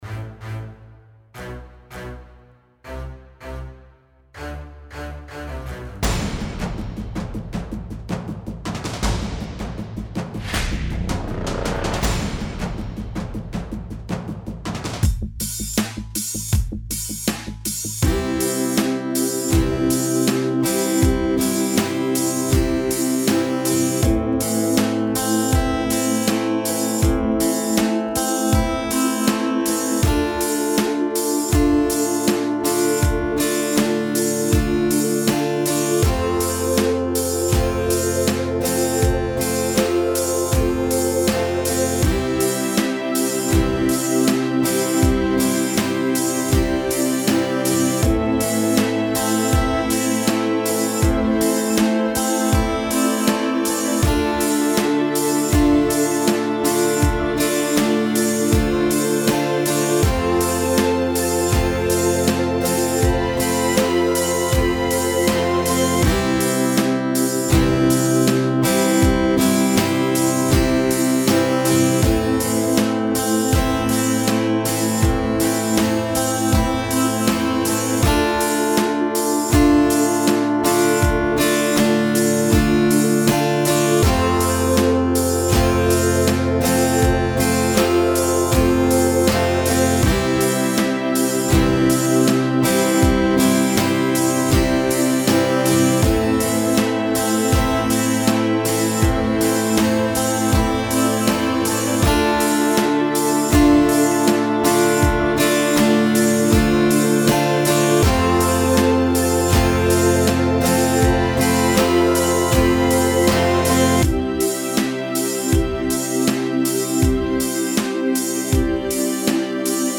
instrumentaler Song